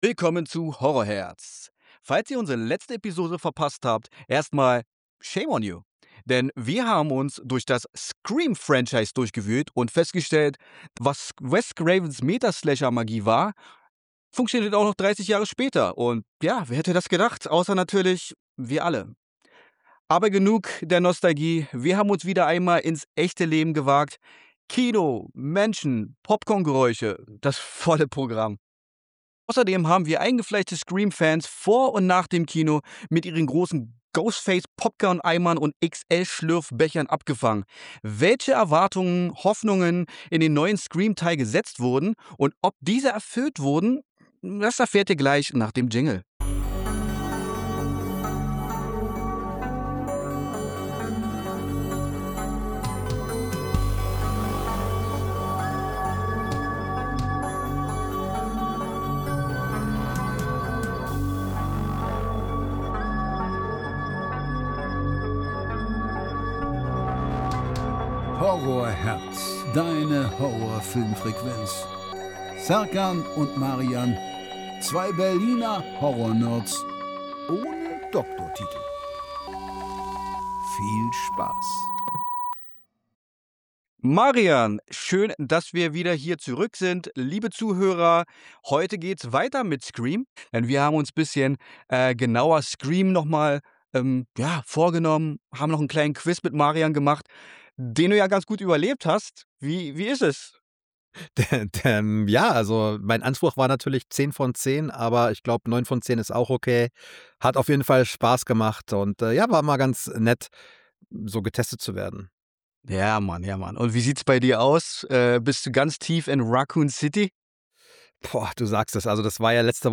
Seit wann hat man eigentlich so hohe Erwartungen an den siebten Teil einer Slasher-Reihe? Wir haben unsere ehrliche Meinung im Gepäck – und direkt vor dem Kino echte Fans abgefangen.